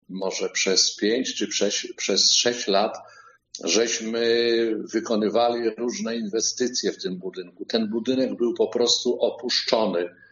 O inwestycji mówi Burmistrz gminy Brok, Marek Młyński: